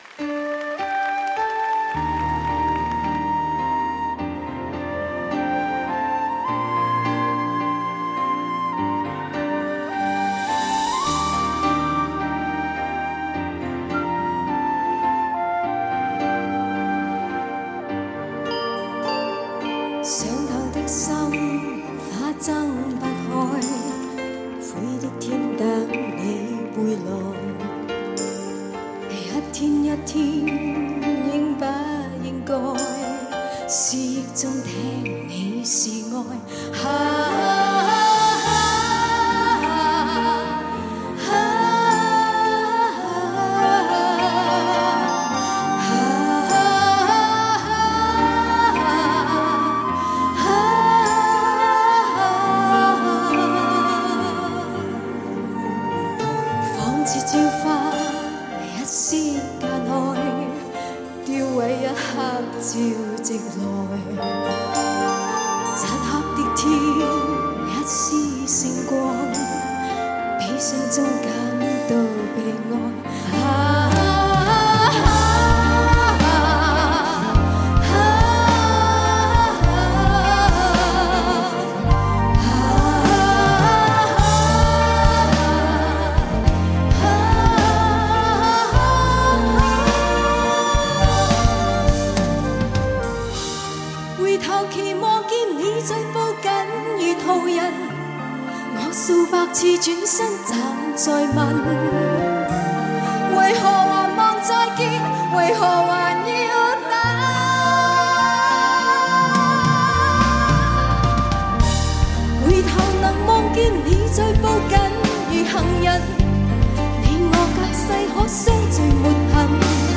首次现场完美演绎
2CD 现场献唱39首经典名曲